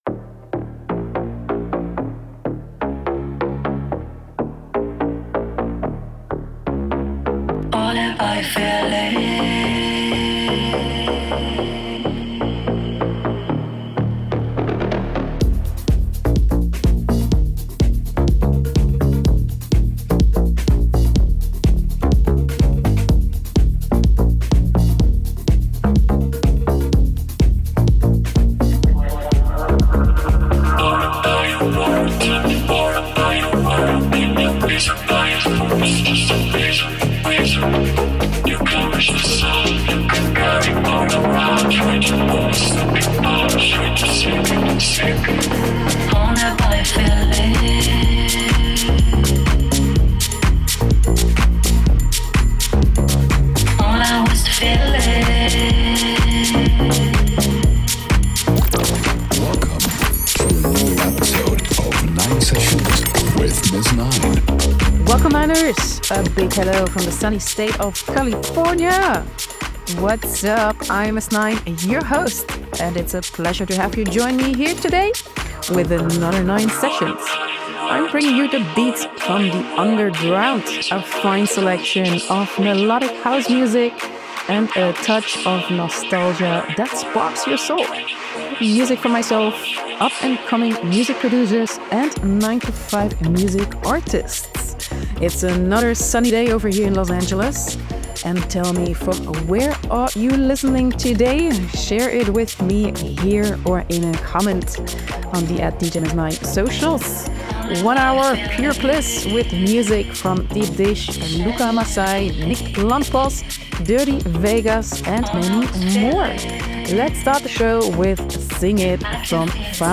Progressive House
Electronic